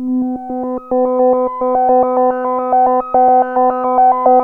JUP 8 B4 11.wav